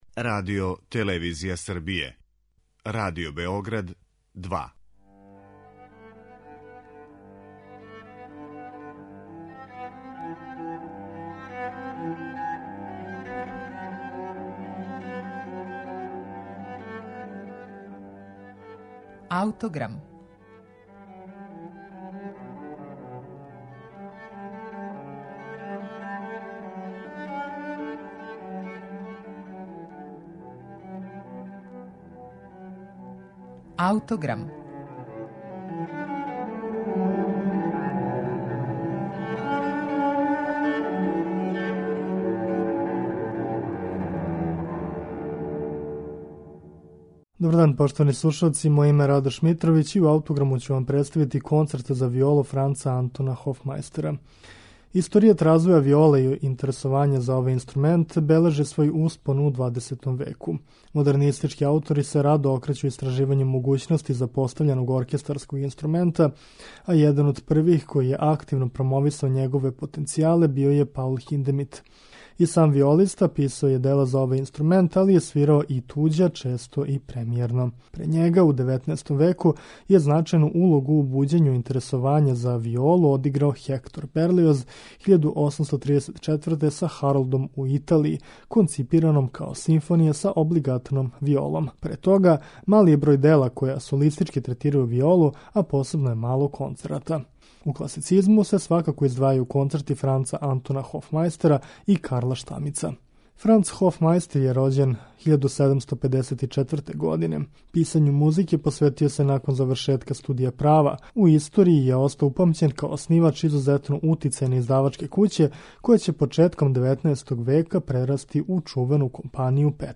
Концерт за виолу у Де-дуру Франца Антона Хофмајстера